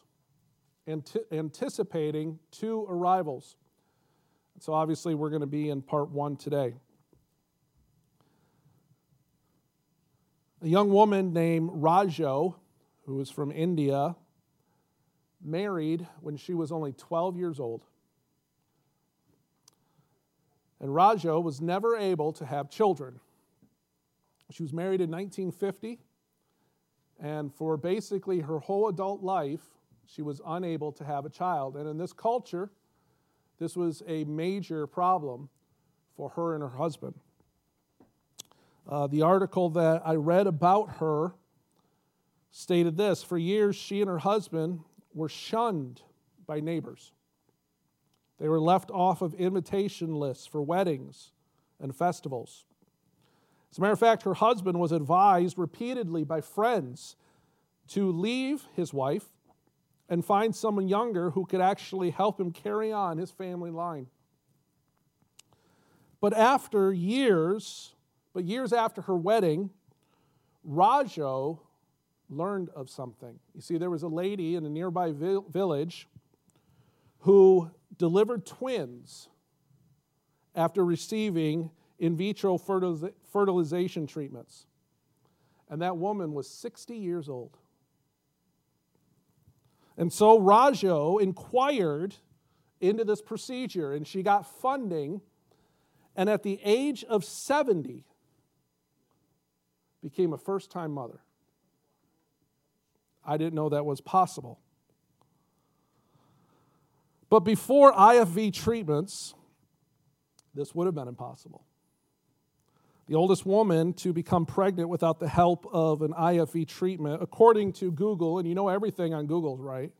Passage: Luke 1:5-25 Service Type: Sunday Morning Topics